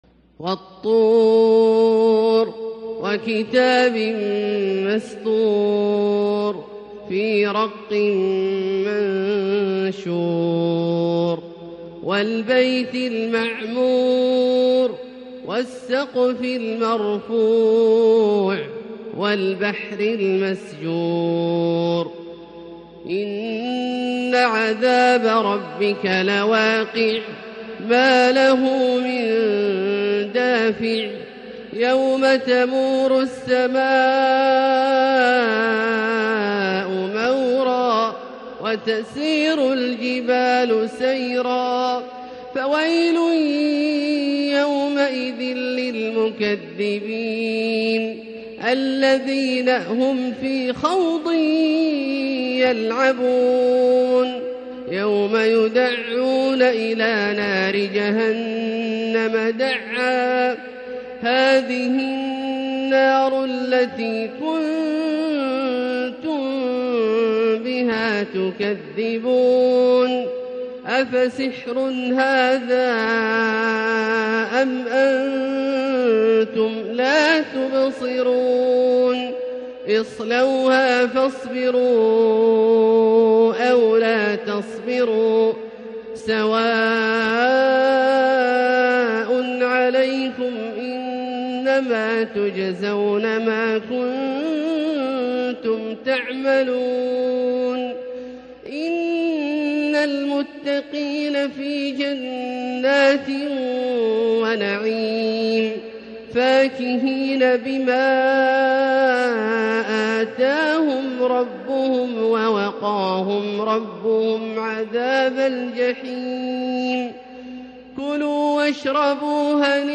تلاوة إبداعية لـ سورة الطور كاملة للشيخ د. عبدالله الجهني من المسجد الحرام | Surat At-Tur > تصوير مرئي للسور الكاملة من المسجد الحرام 🕋 > المزيد - تلاوات عبدالله الجهني